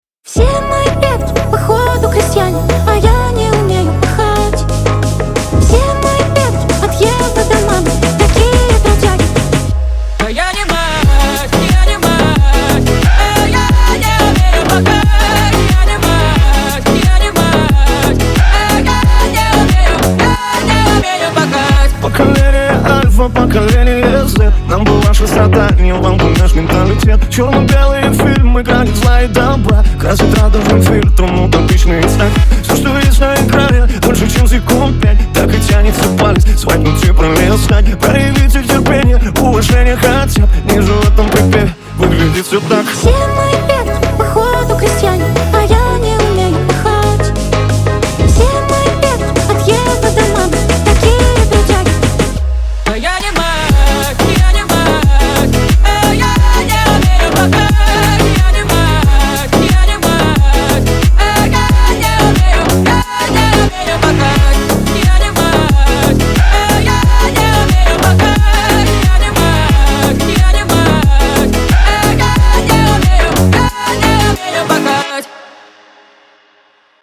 Лирика , весёлая музыка
pop